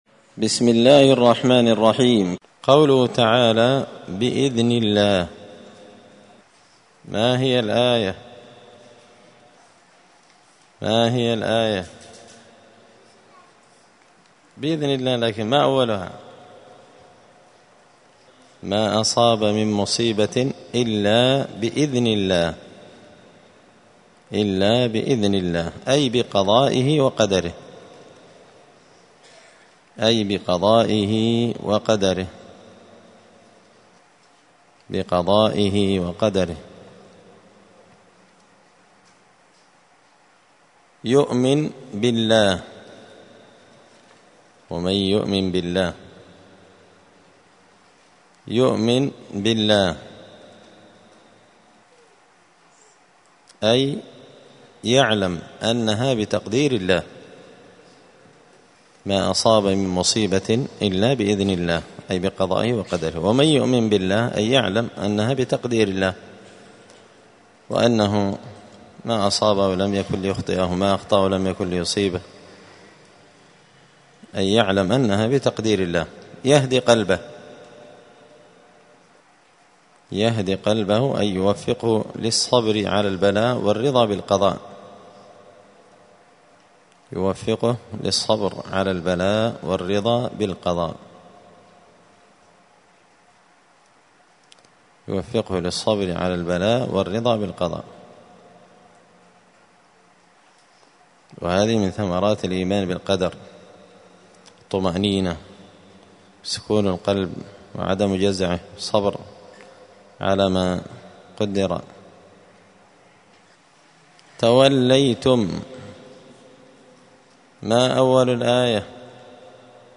الأحد 22 شعبان 1445 هــــ | الدروس، دروس القران وعلومة، زبدة الأقوال في غريب كلام المتعال | شارك بتعليقك | 25 المشاهدات